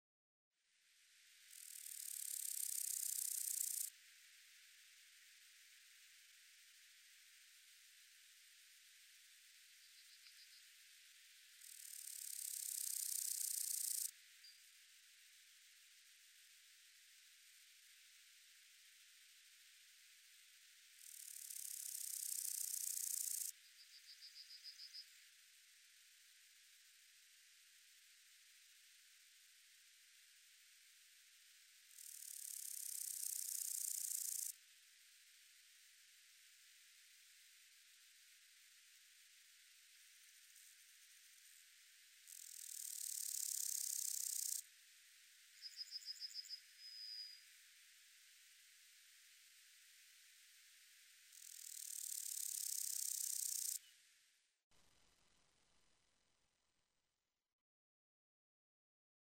Lille lynggræshoppe
Lyt til den lille lynggræshoppe.
lille-lynggræshoppe.mp3